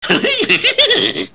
scoob_laugh.wav